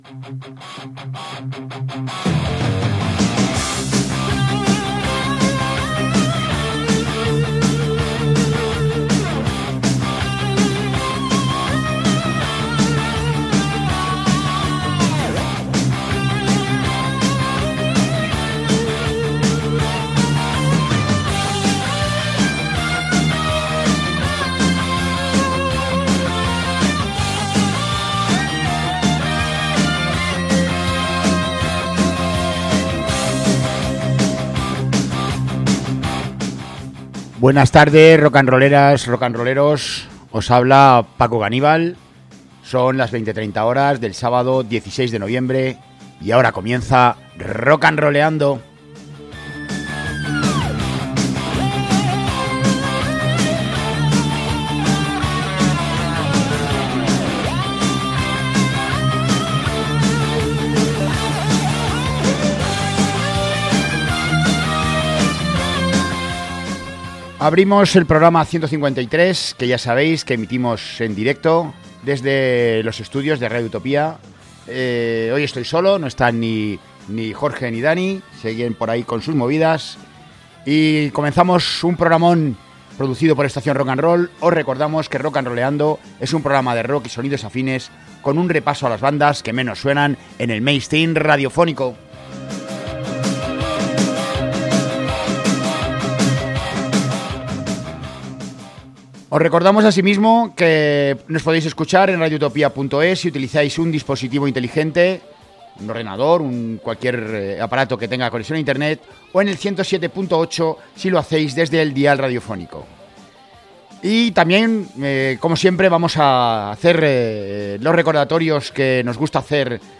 mezclando guitarras difusas
preciosa y suave balada